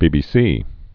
(bēbē-sē)